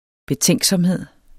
Udtale [ beˈtεŋˀgsʌmˌheðˀ ]